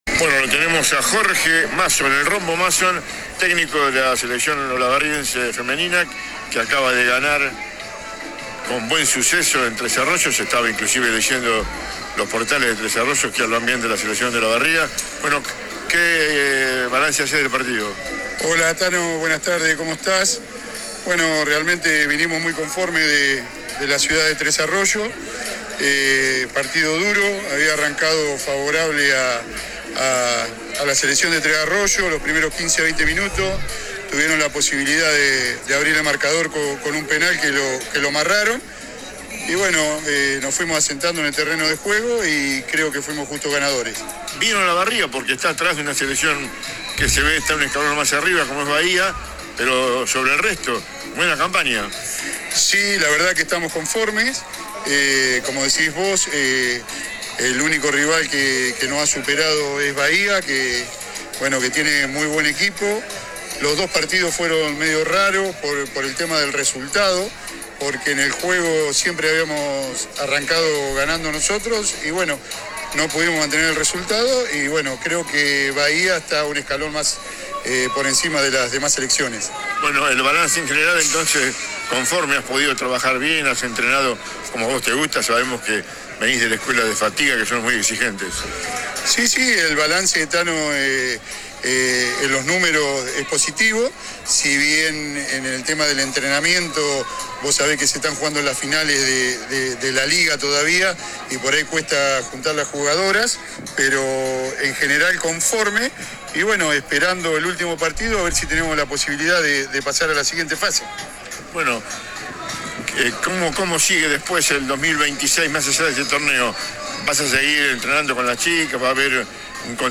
AUDIO DE LA ENTREVISTA